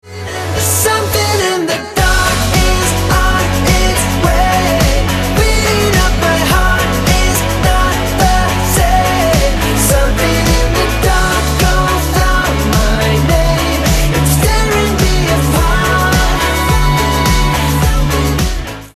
• Качество: 128, Stereo
мужской вокал
громкие
Alternative Rock